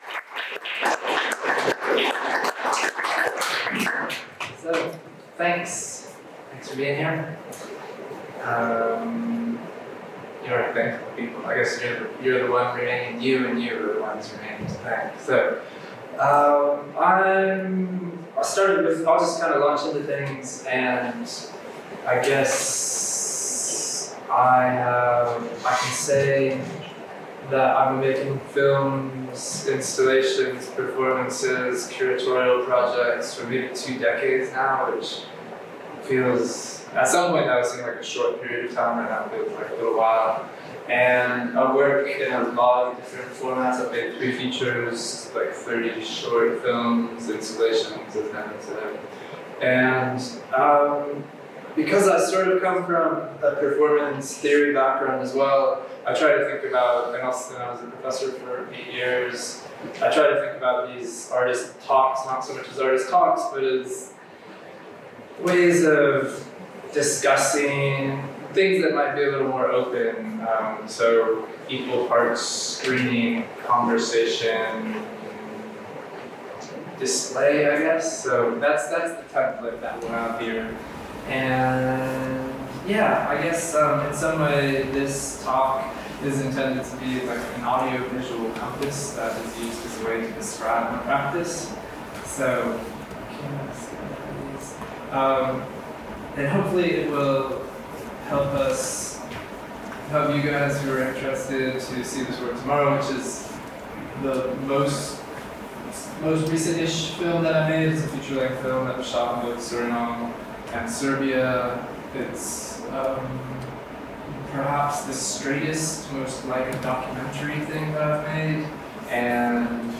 Knot project space host renowned American artist and filmmaker, Ben Russell, for a special presentation about his work.